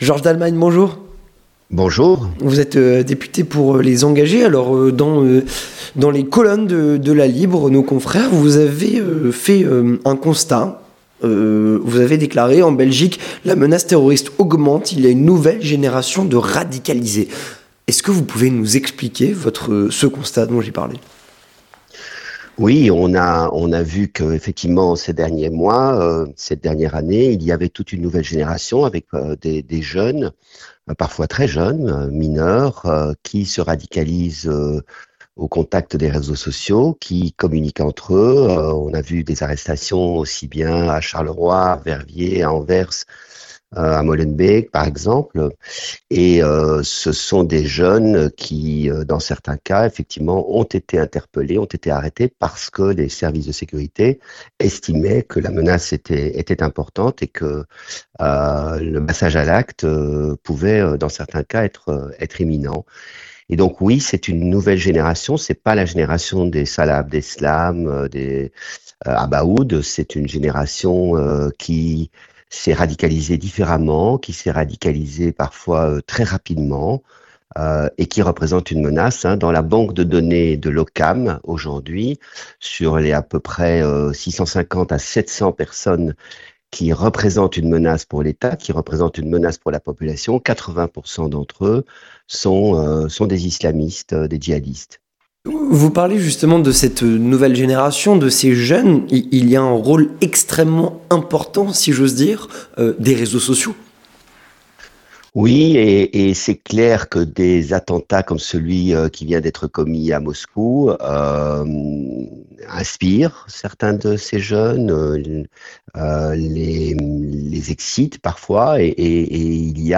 Avec Georges Dallemagne, Député "Les Engagés", qui est notre invité.